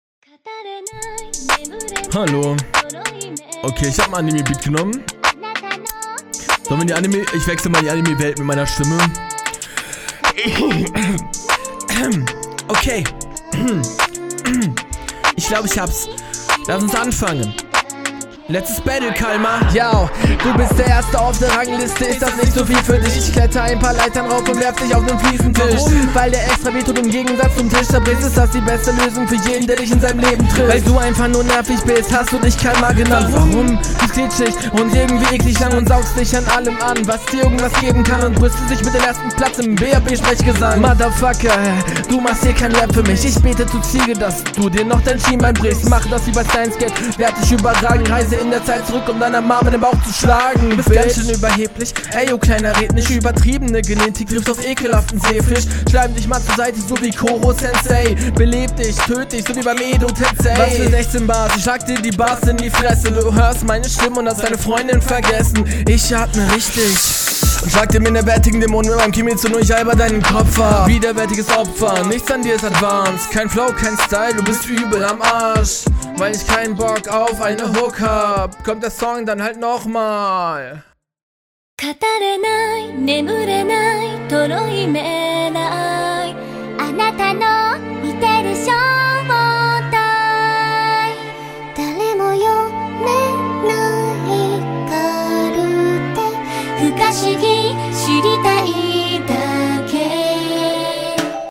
Flow an einigen stellen sehr offbeat und generell stolperst du dem Beat bisschen hinterher. aber …